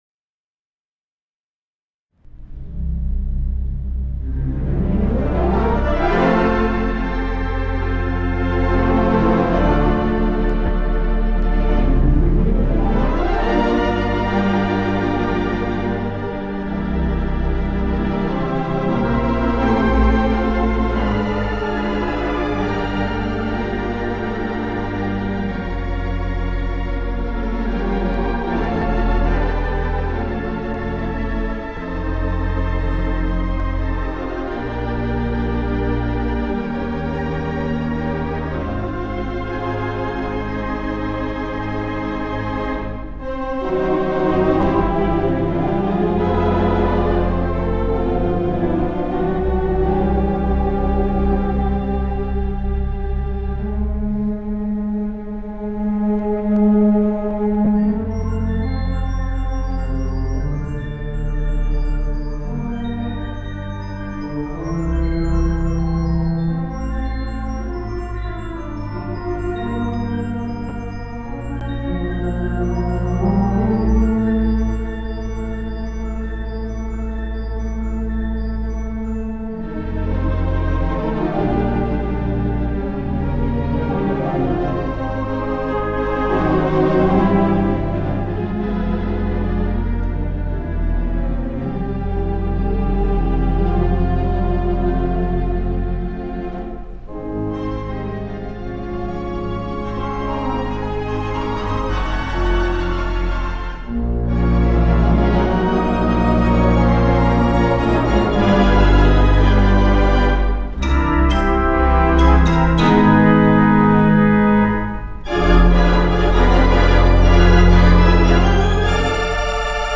Listen to the 4/28 Mighty WurliTzer Theatre Pipe Organ installed at the Alabama Theatre in Birmingham, Alabama!
These tracks were recorded live on his Dell laptop computer using a Berhinger USB mixer and CAD condenser microphones.
There are some pops and crackles here and there that our software would not remove.